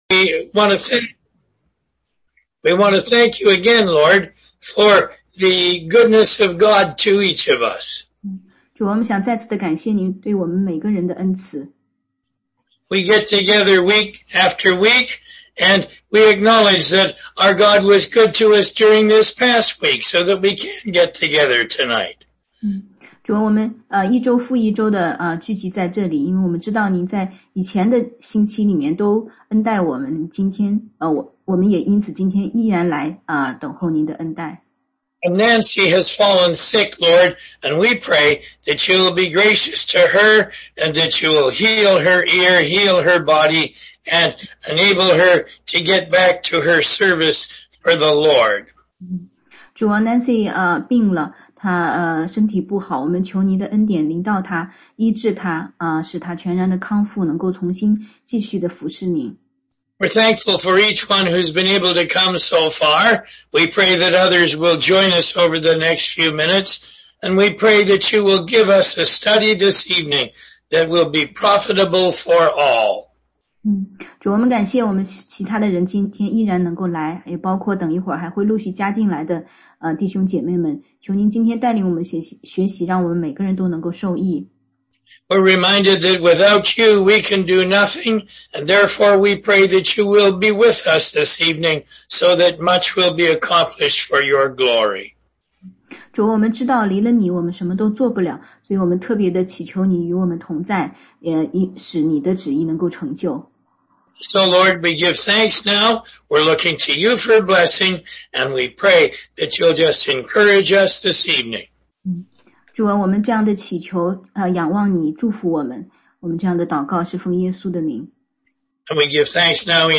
16街讲道录音 - 关于教会的真理系列之五：地方教会如何见证“每个信徒都是身体的肢体”的真理